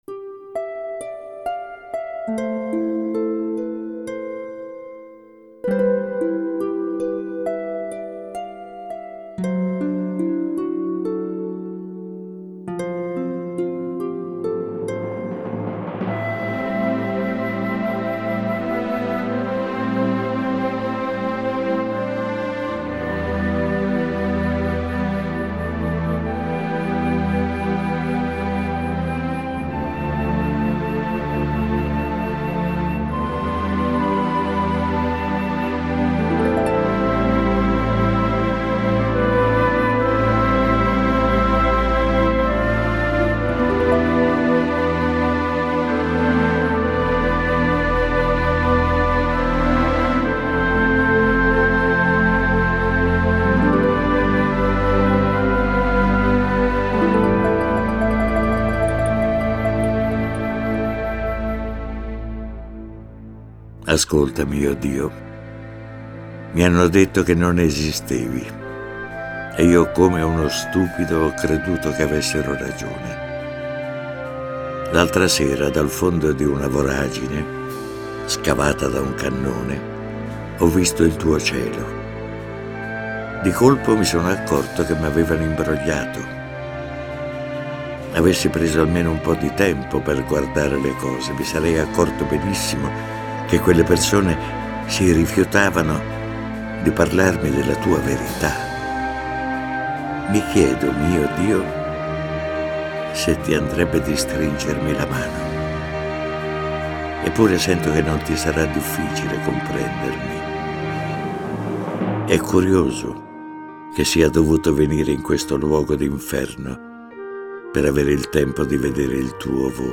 La poesia che mi commuove ogni volta che l'ascolto recitata da Arnoldo Foà: lettera di un soldato.